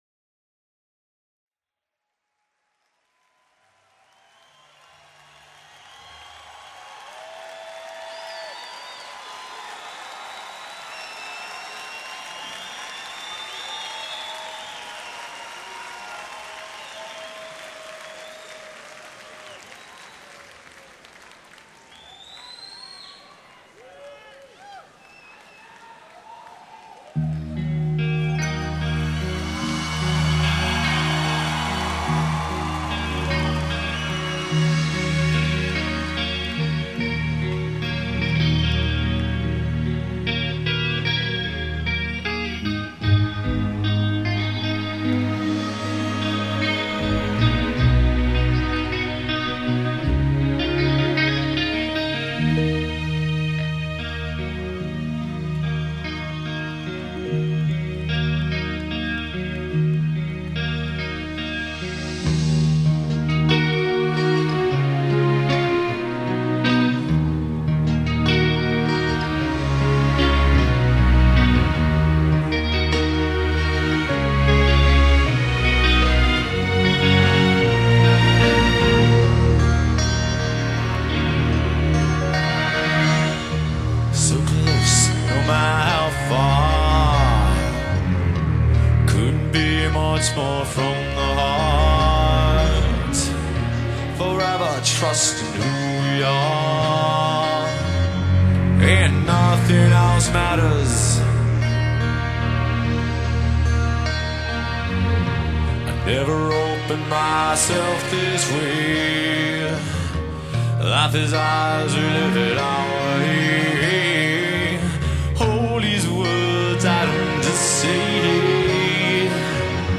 主 唱/节奏吉它
鼓 手
主音吉它
贝 司